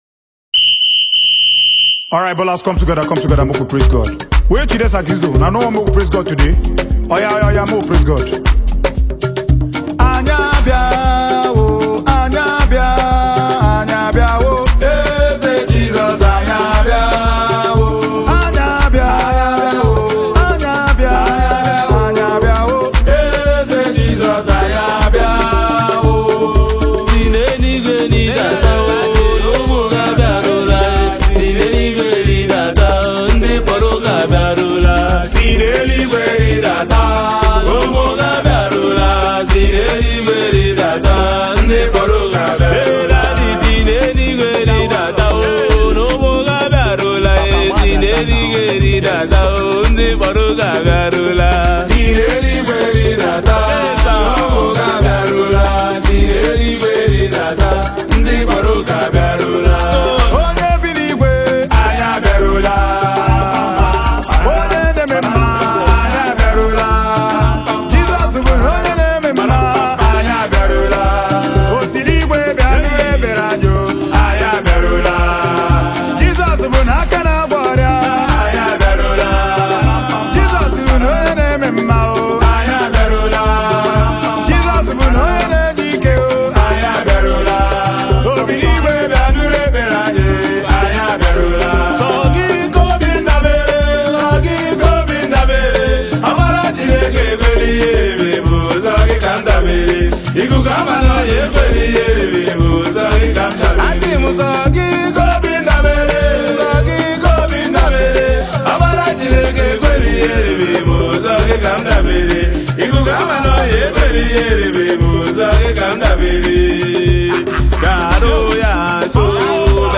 contemporary gospel